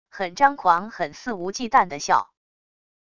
很张狂很肆无忌惮的笑wav音频